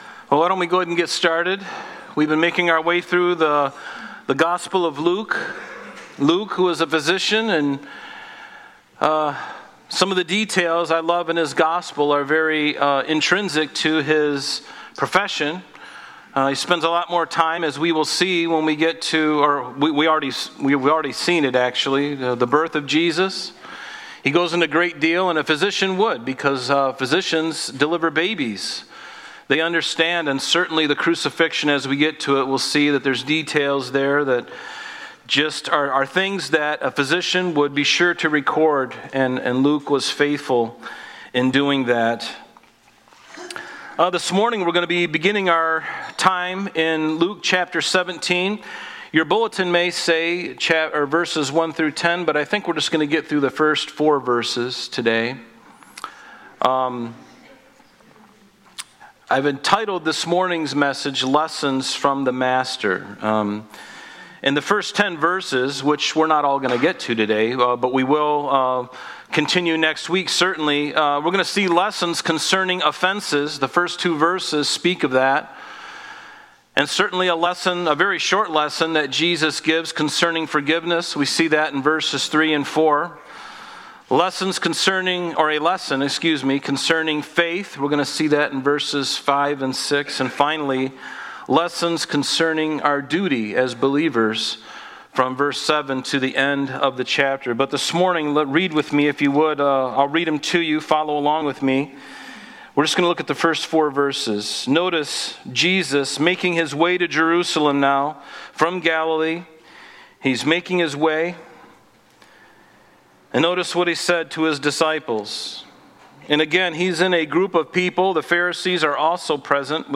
Sunday Morning Service